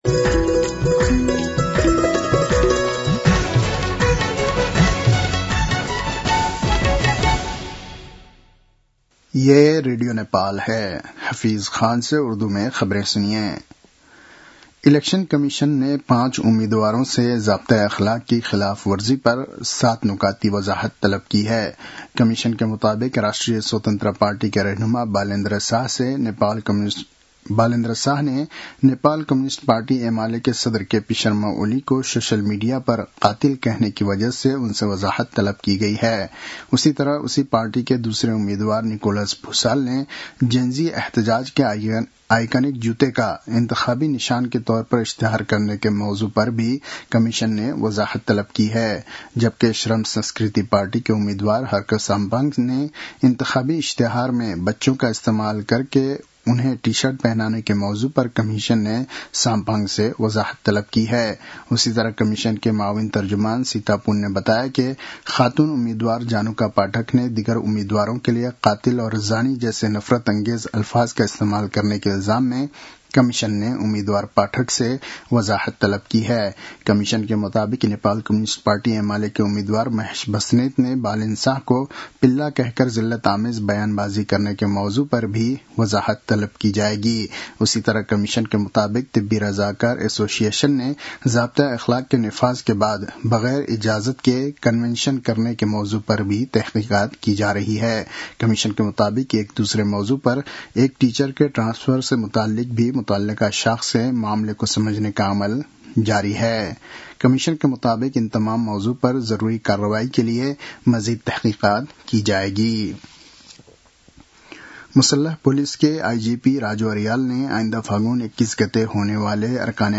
उर्दु भाषामा समाचार : १३ माघ , २०८२